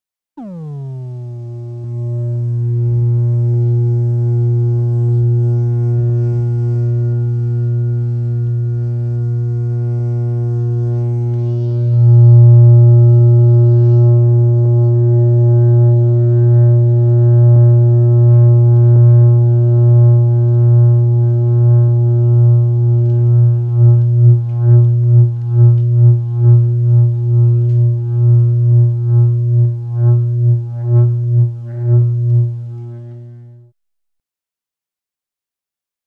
Low Frequency Modulation 3; Electrical Power Surge; Oscillation, Sweeping Down, Then Pulsating; Classic Sci-fi Throw The Switch Effect, Close Perspective.